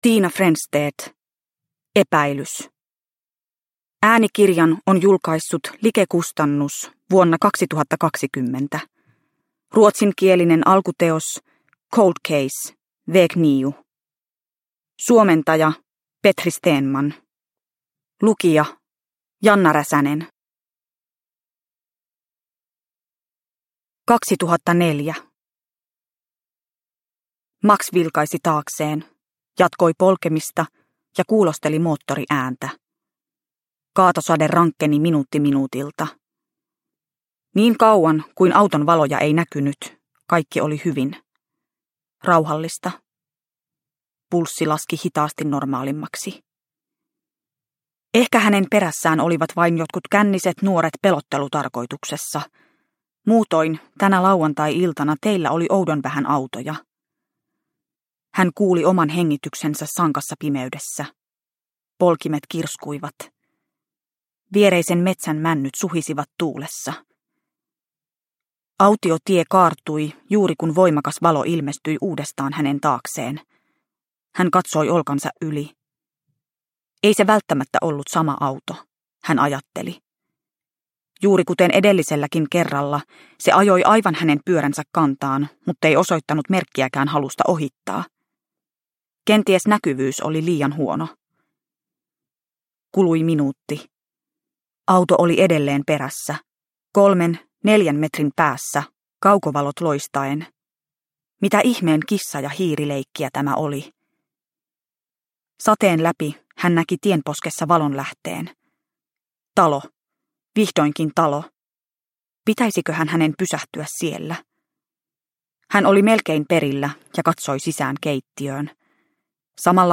Epäilys – Ljudbok – Laddas ner